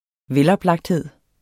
Udtale [ ˈvεlʌbˌlɑgdˌheðˀ ]